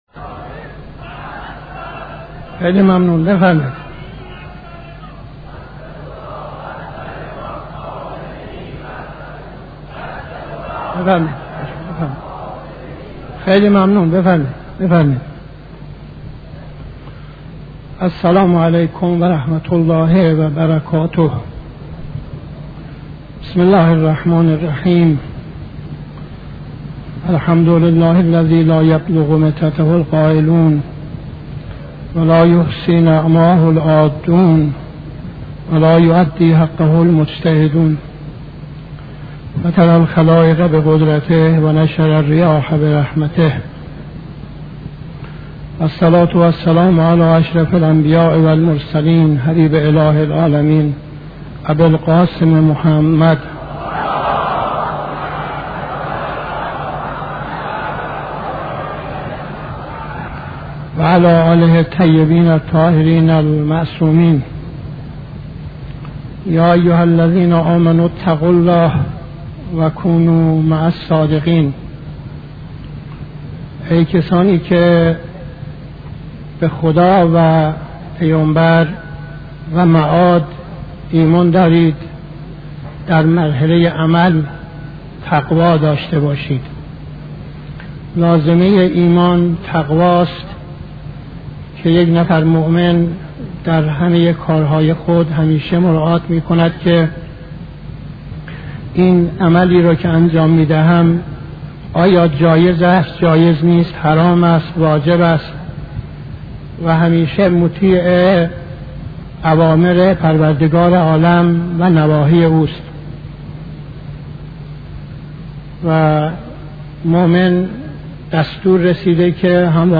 خطبه اول نماز جمعه 24-07-71